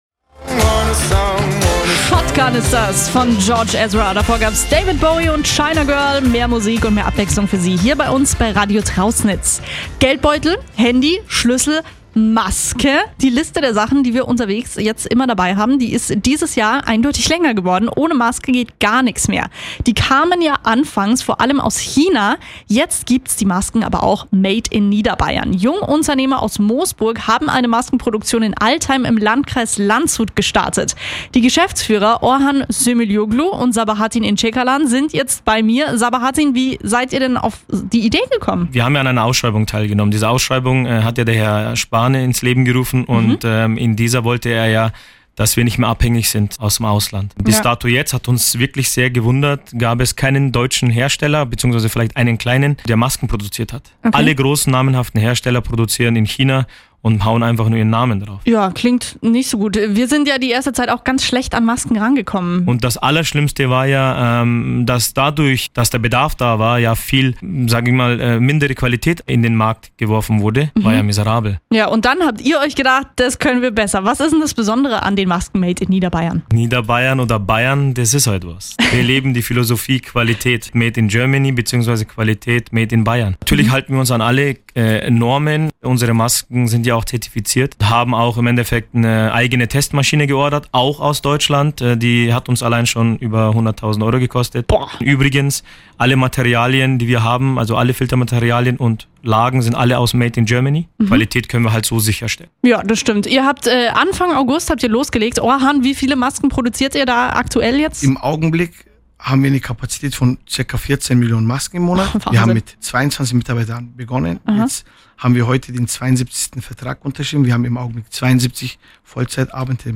Radio Trausnitz – Bericht über die Maskenproduktion